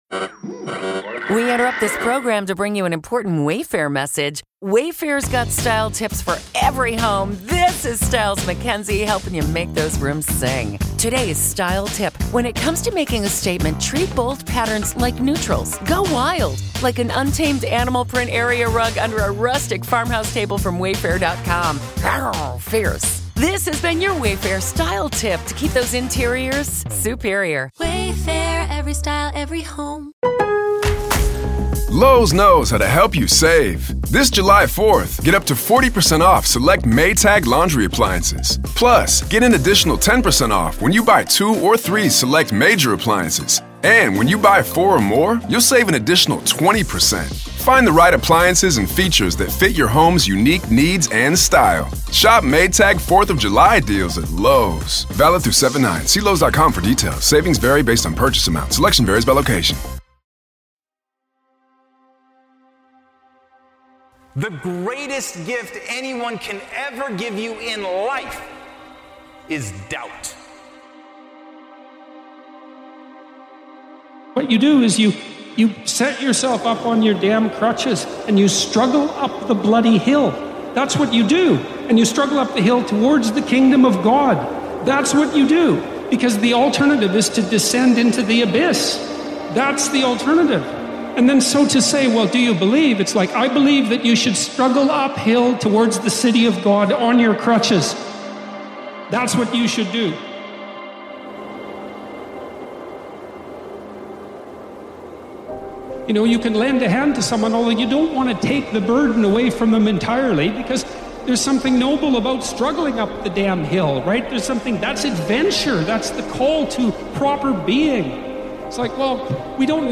| Unstoppable Motivation for Daily Grind – Motivational Speech – Podcast – Podtail